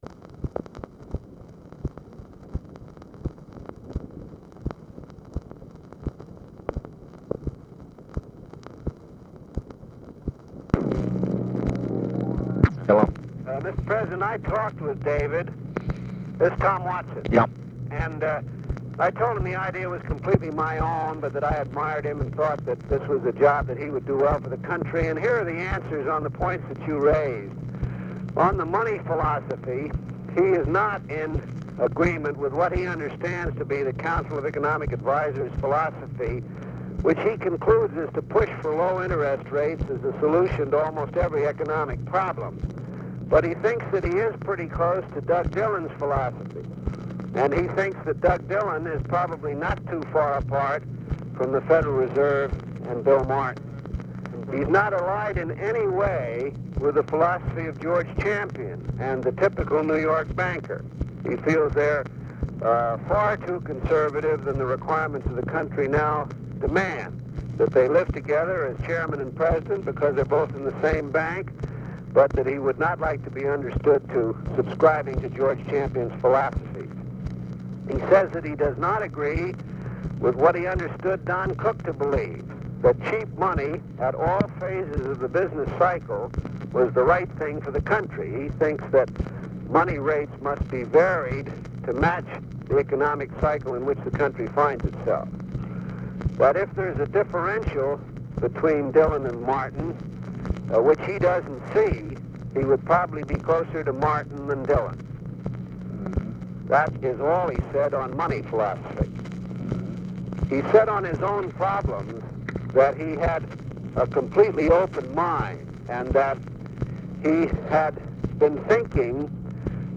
Conversation with TOM WATSON, March 17, 1965
Secret White House Tapes